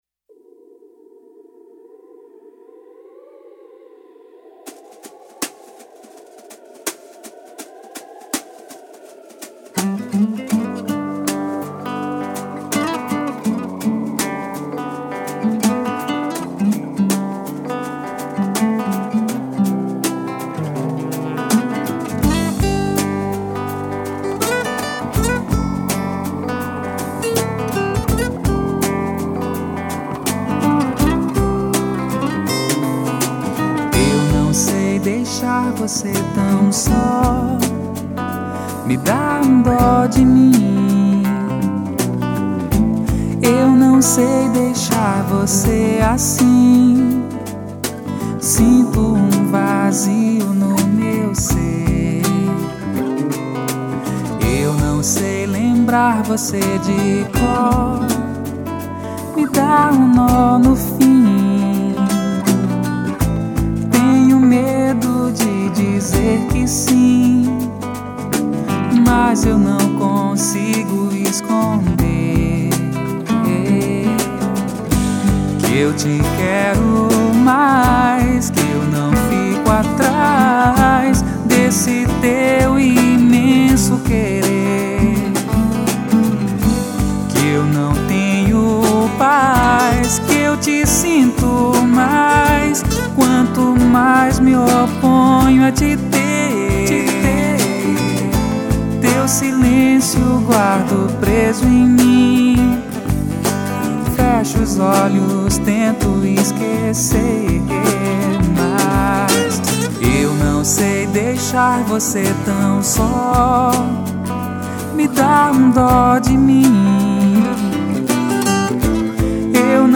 831   07:10:00   Faixa:     Mpb